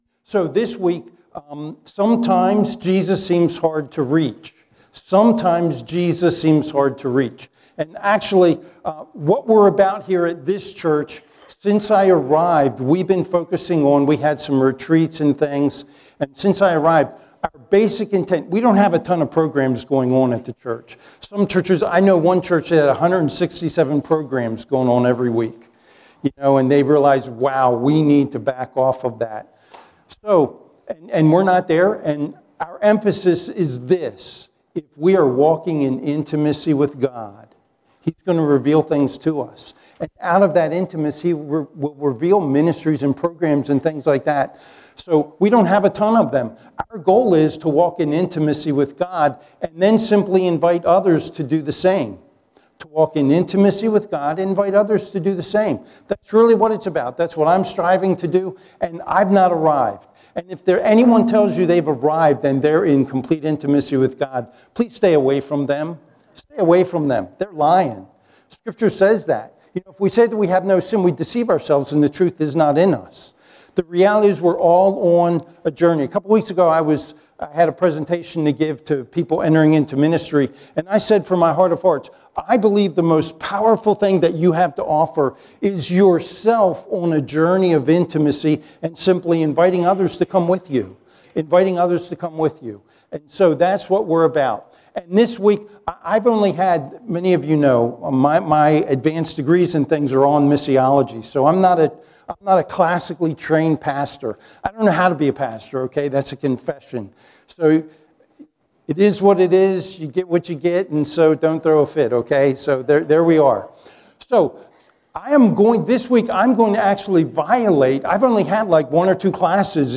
Sermons | Buckhannon Alliance Church